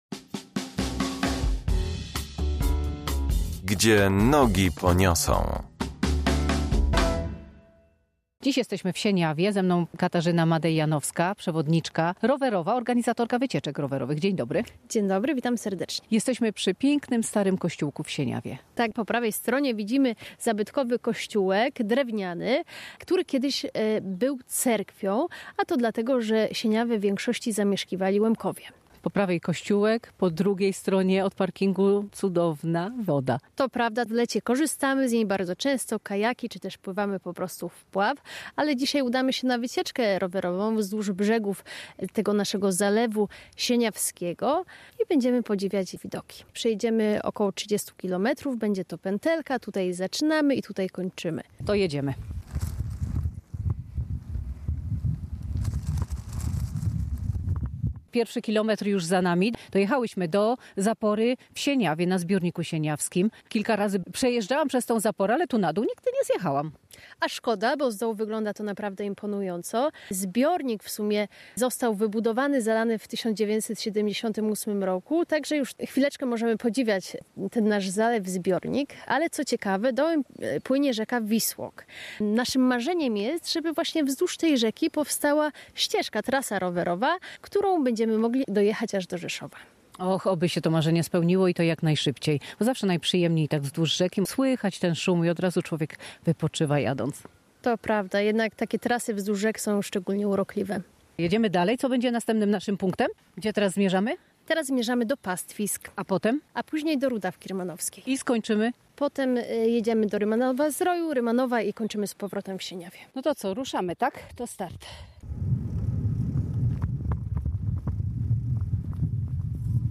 Audycja „Gdzie nogi poniosą” w każdą sobotę o godz. 11.30.